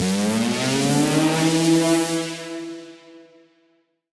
Index of /musicradar/future-rave-samples/Siren-Horn Type Hits/Ramp Up
FR_SirHornF[up]-E.wav